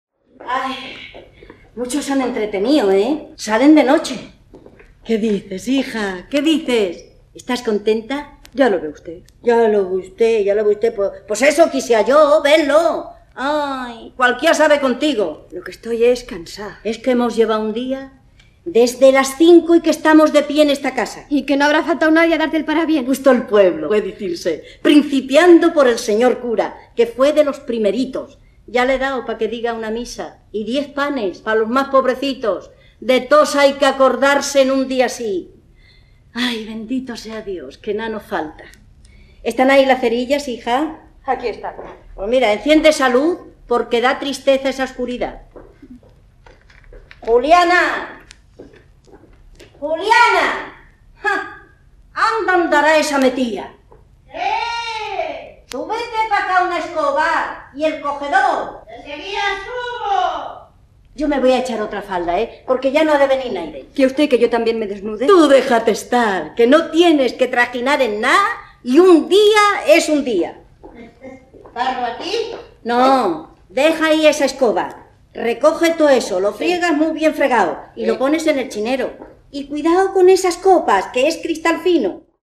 Teatro invisible: La Malquerida (interpreta doña Isabel)